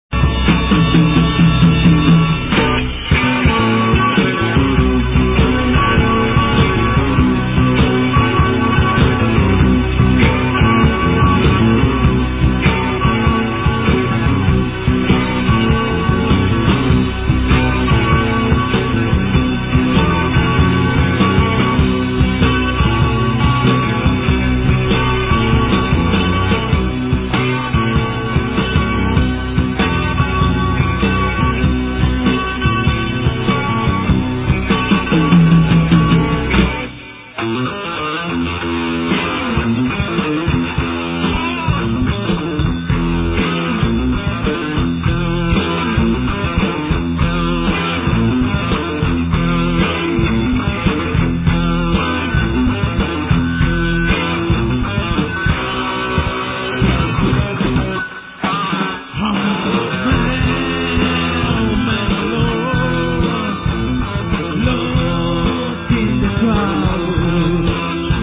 o quarto disco ao vivo